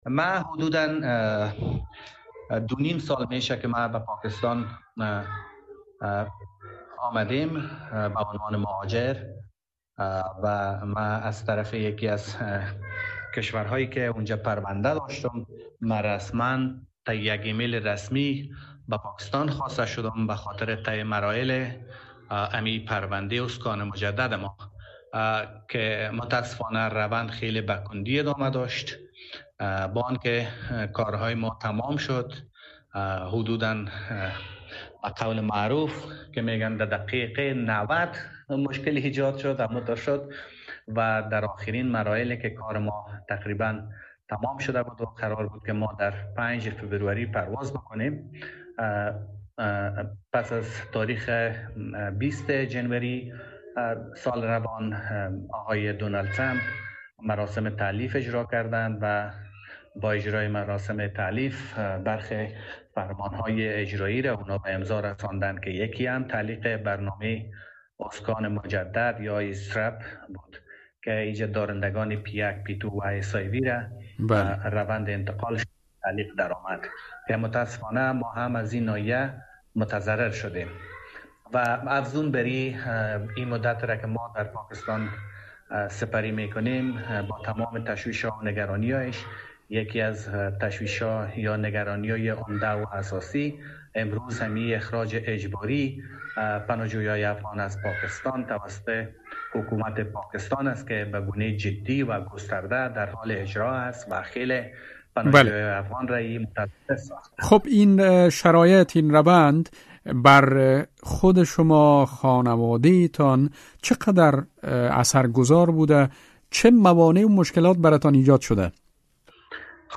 مصاحبه با یک مهاجر افغان در پاکستان